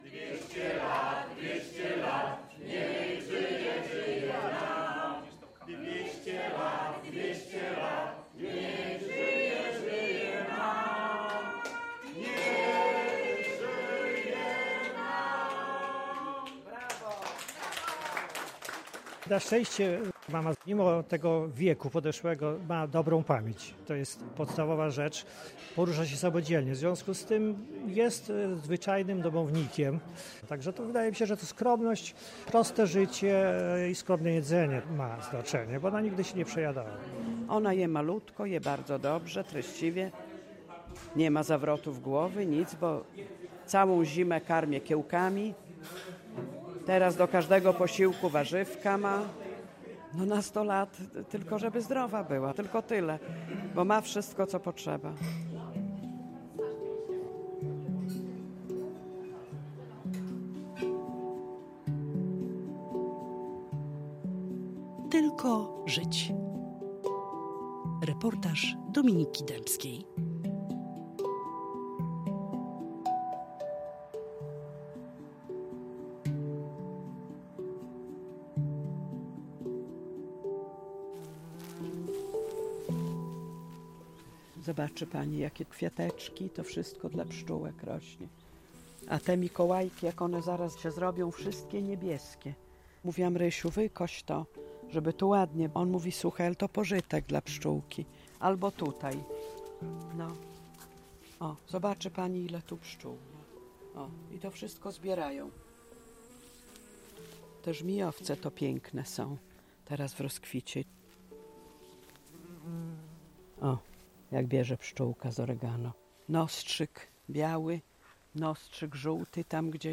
Prezentujemy zwycięski reportaż w kategorii EkoBałtyk.